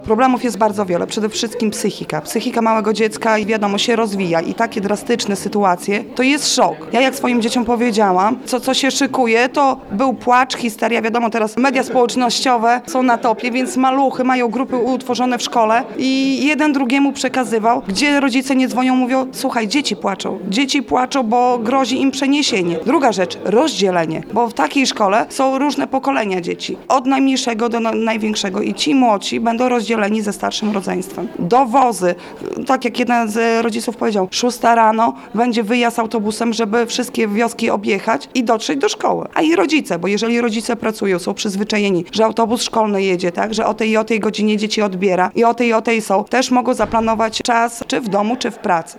mówiła w rozmowie z reporterem Radia 5